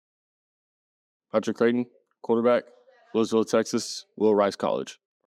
Pronunciation Guide